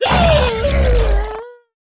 naga_commander_die.wav